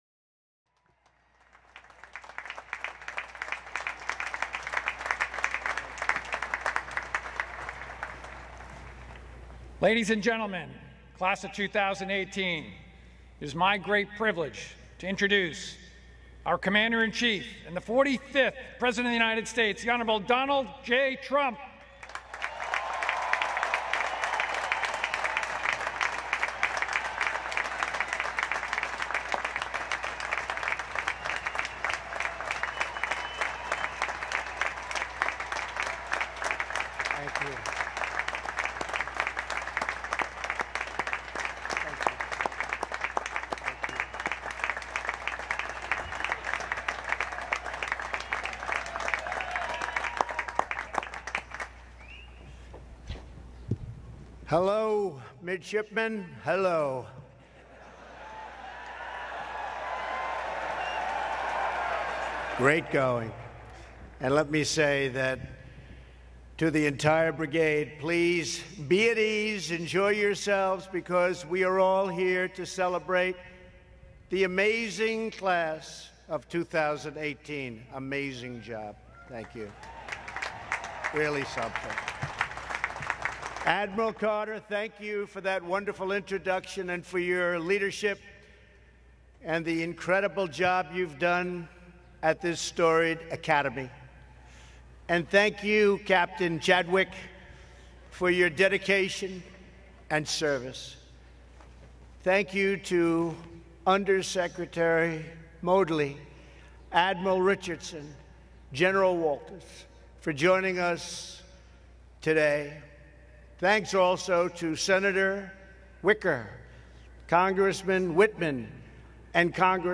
U.S. President Donald Trump speaks at the U.S. Naval Academy 2018 commencement ceremony